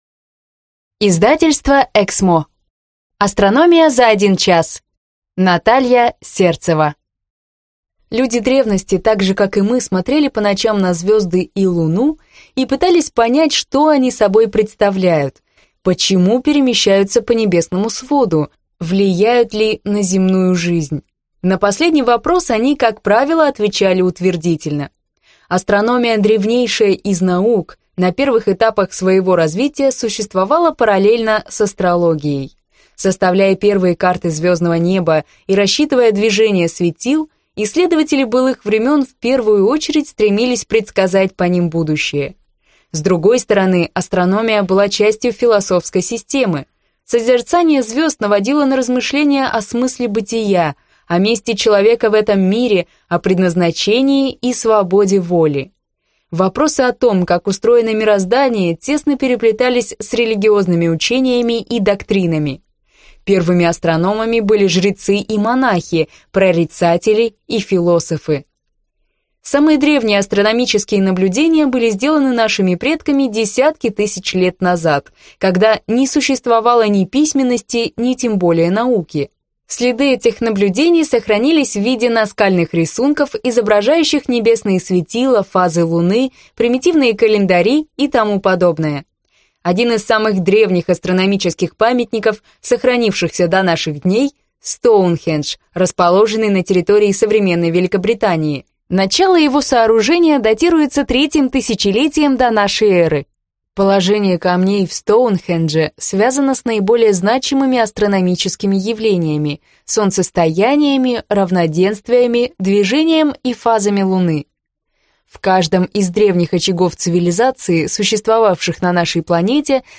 Аудиокнига Астрономия за 1 час | Библиотека аудиокниг